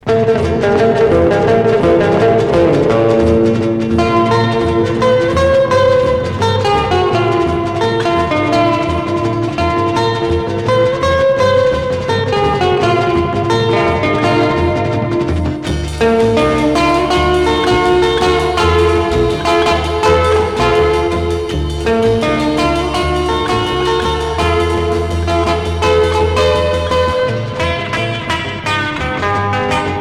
Rock instrumental Unique EP retour à l'accueil